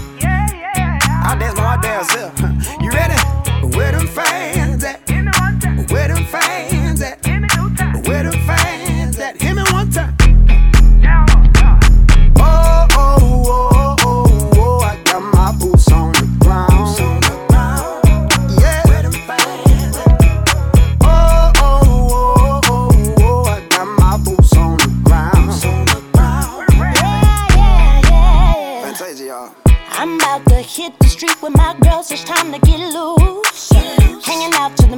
Country Blues